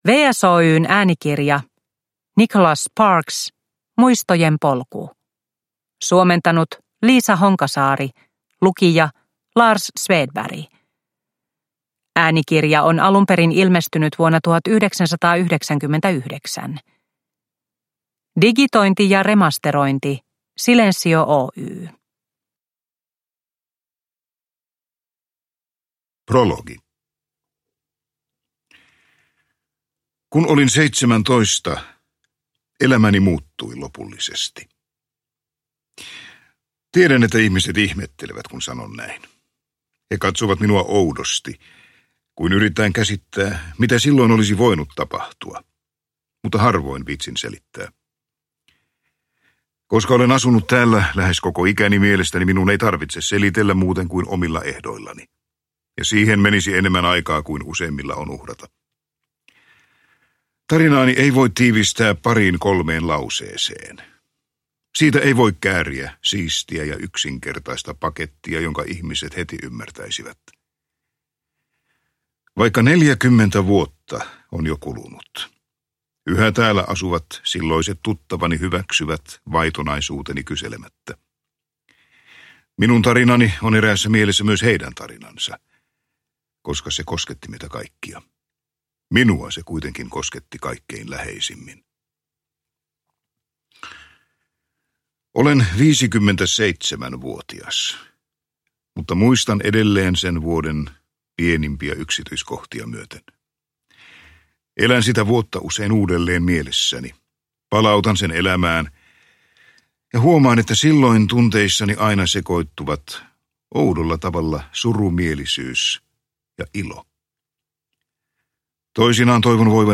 Muistojen polku – Ljudbok – Laddas ner